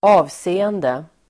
Uttal: [²'a:vse:ende]